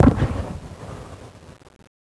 city_battle18.wav